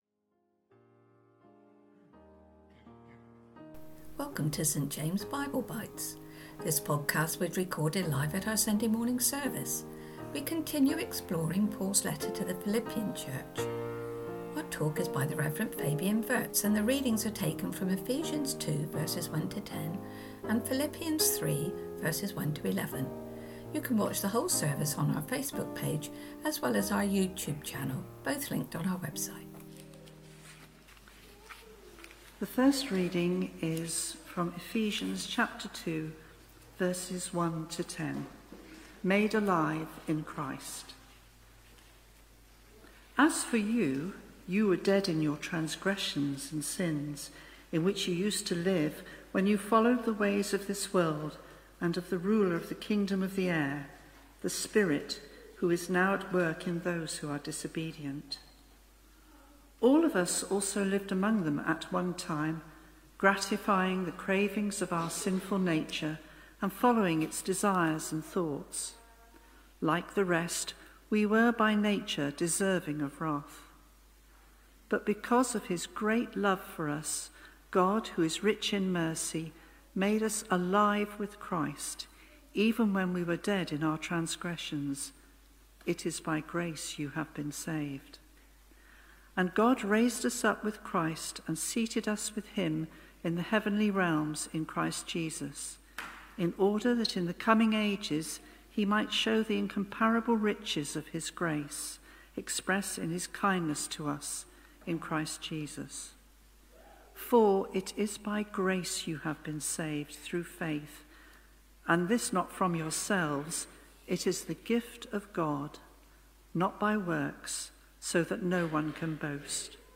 Sunday Talks